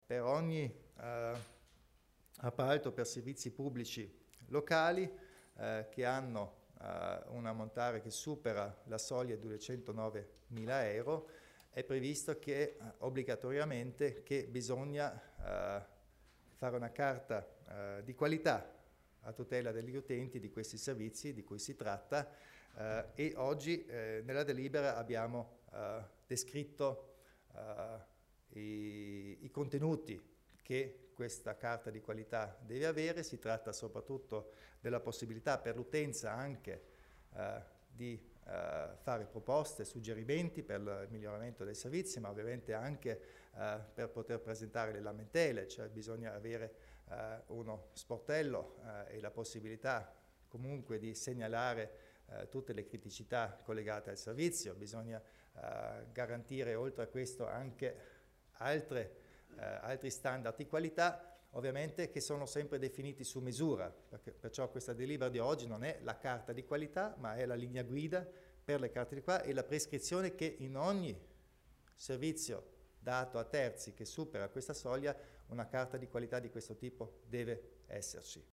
Il Presidente Kompatscher spiega la nuova Carta di qualità dei servizi